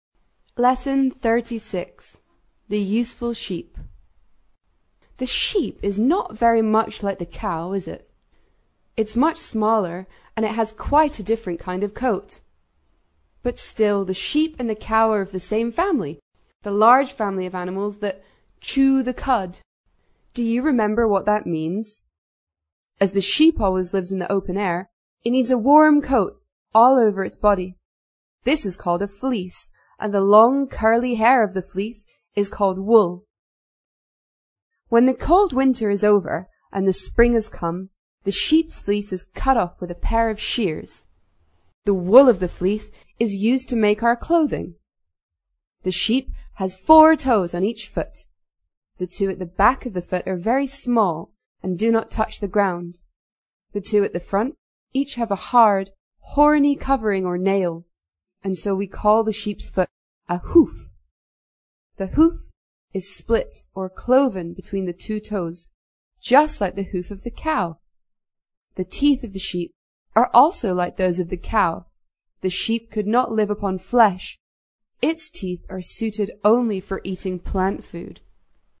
在线英语听力室英国学生科学读本 第36期:浑身是宝的绵羊(1)的听力文件下载,《英国学生科学读本》讲述大自然中的动物、植物等广博的科学知识，犹如一部万物简史。在线英语听力室提供配套英文朗读与双语字幕，帮助读者全面提升英语阅读水平。